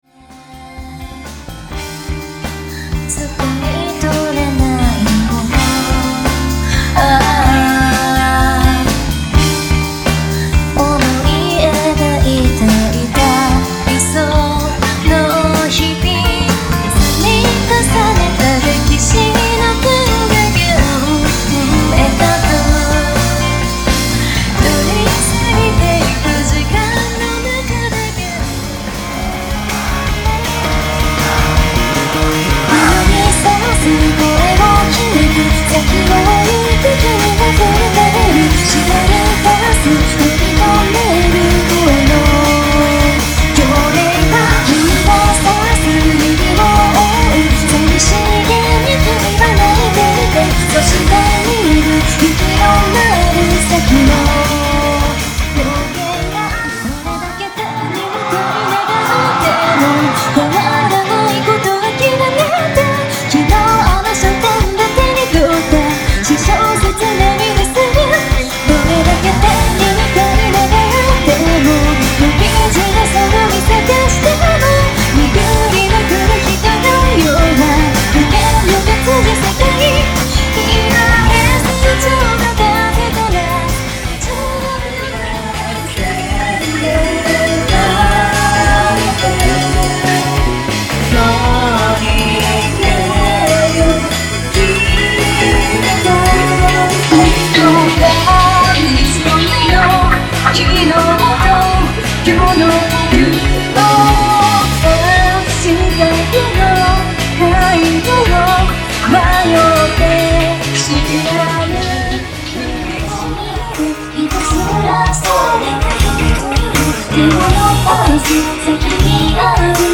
2024年12月1日　博麗神社うた祭2024 in 東京　CLUB CITTA’（川崎）
各ライブで演奏した全曲を収録（計9曲）。
※記録映像の音源を編集して制作しているため、録音時や編集時のノイズなども含まれています。